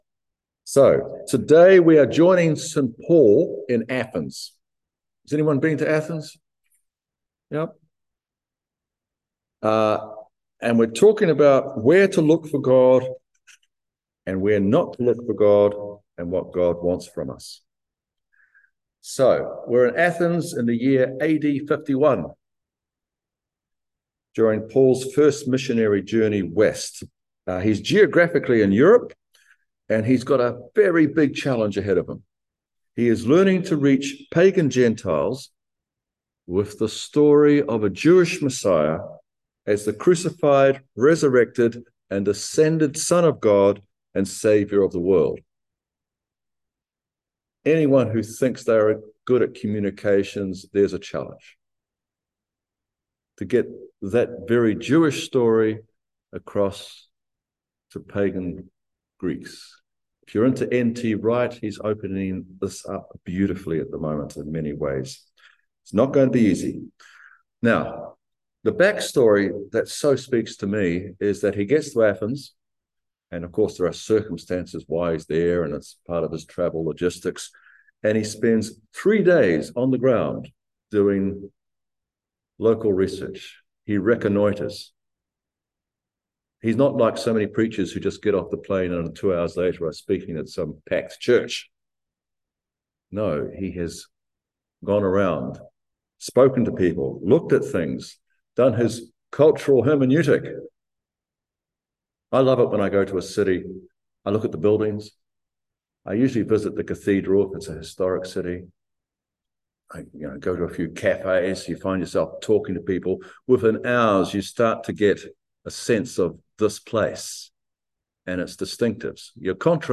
John 14:15-21 Service Type: Morning Worship Download Files Notes « Trinity